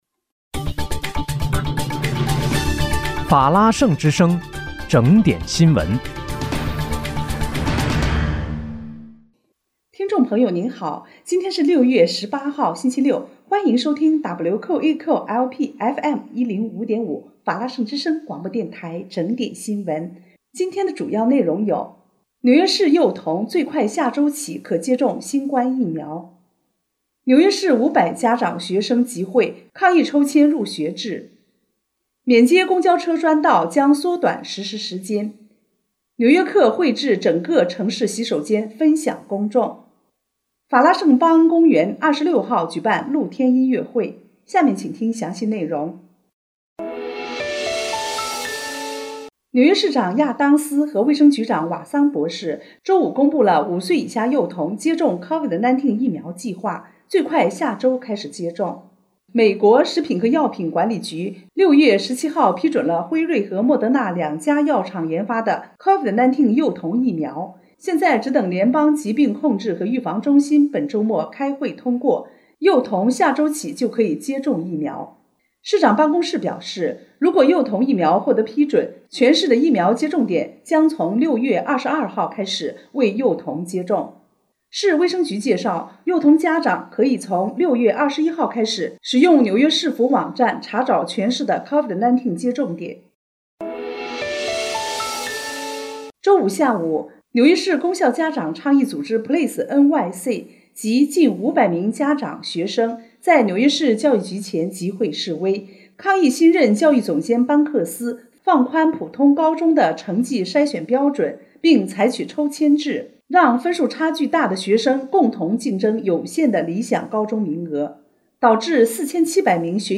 6月18日（星期六）纽约整点新闻